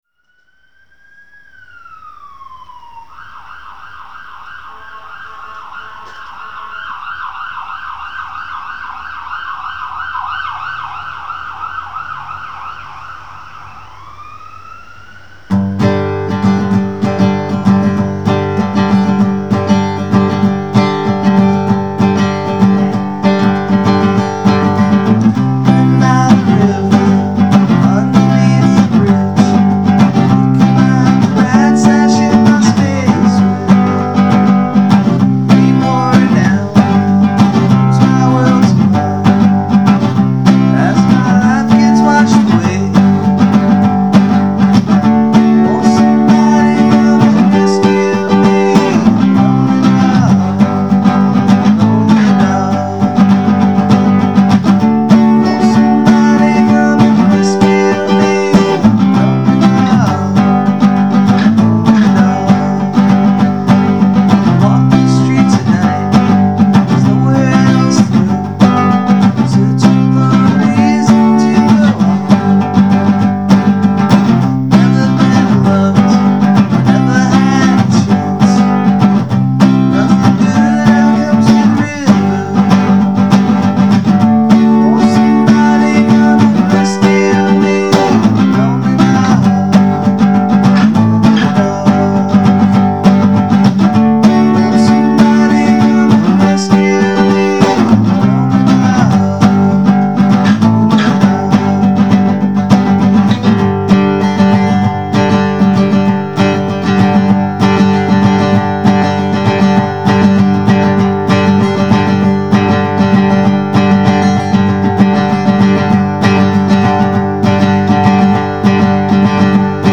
Rock & Roll
Unplugged